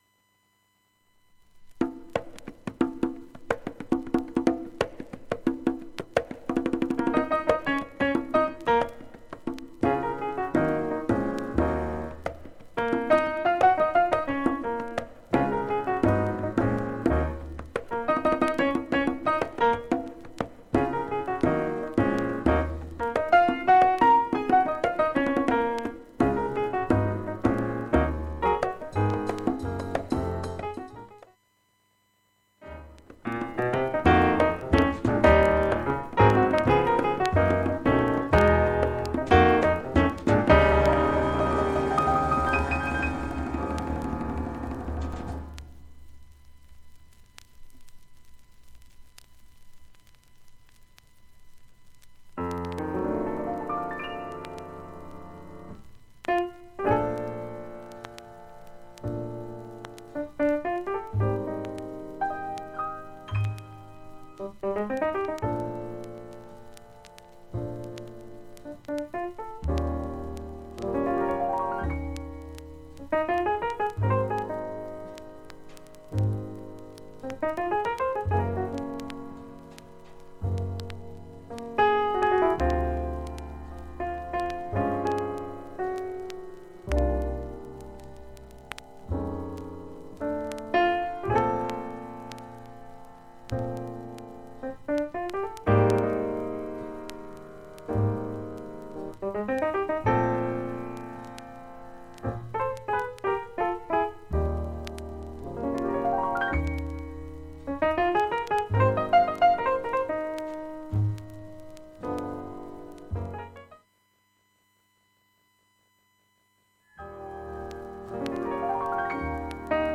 結構クリアな音質です。
（大半がピアノのみなど静かな部です）
周回プツが出ますがかなり小さいです。